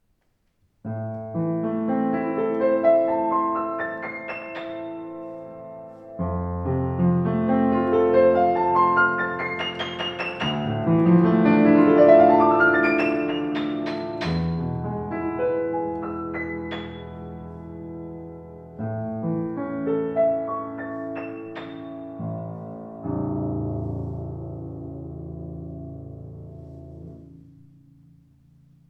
Warmer, ausgewogener Klang mit satten Bässen.